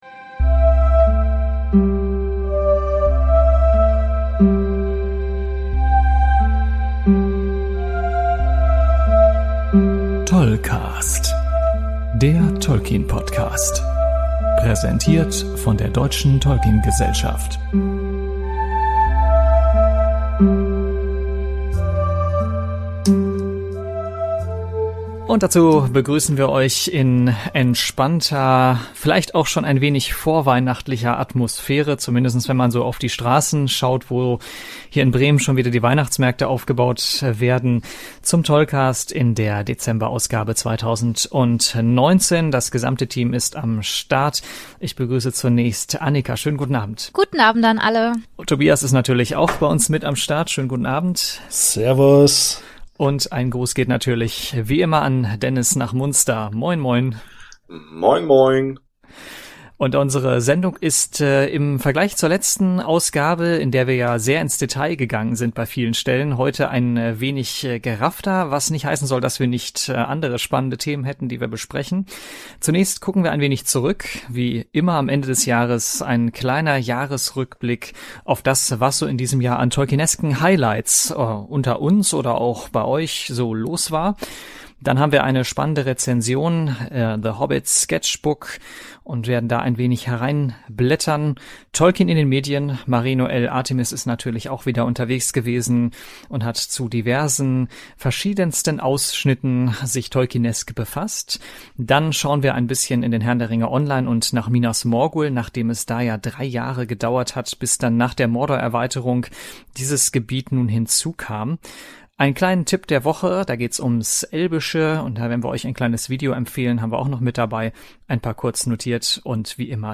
Es wird in gewohnter Manier gelacht und geneckt.